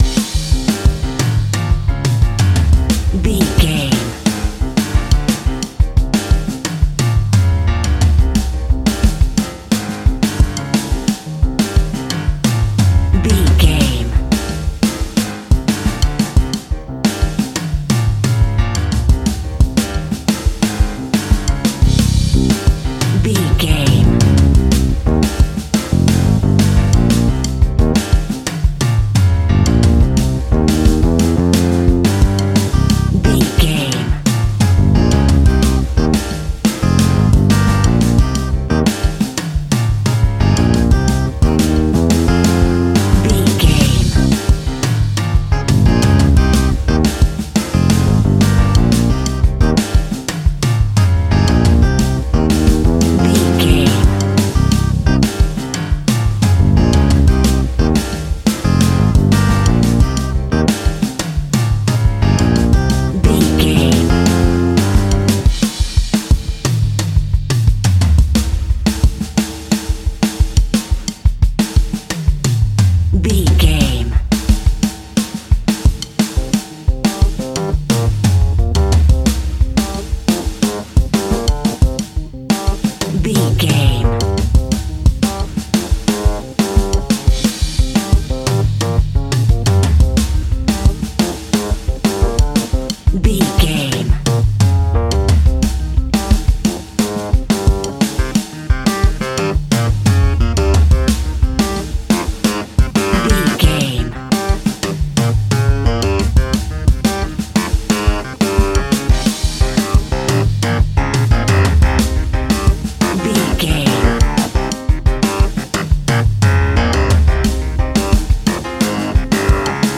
Aeolian/Minor
flamenco
latin
salsa
uptempo
drums
bass guitar
percussion
brass
saxophone
trumpet